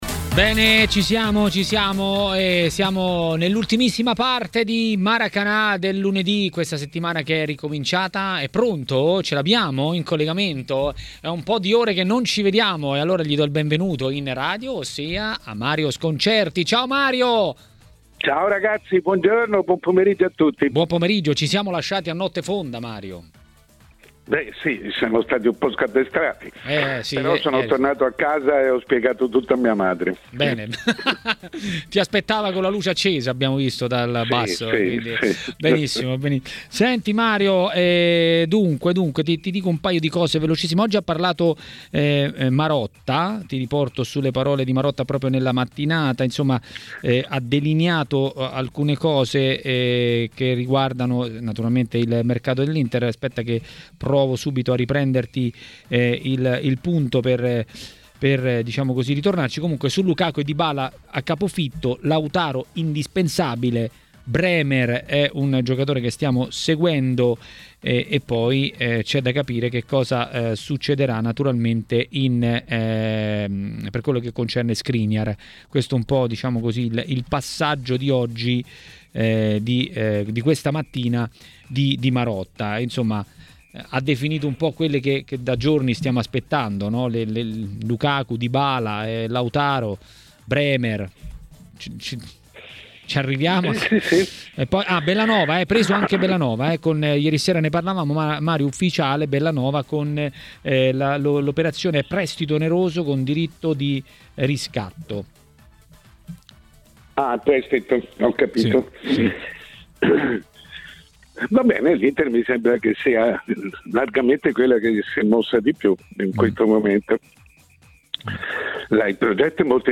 A parlare dei temi del giorno a Maracanà, trasmissione di TMW Radio, è stato il direttore Mario Sconcerti.